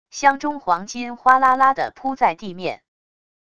箱中黄金哗啦啦的铺在地面wav音频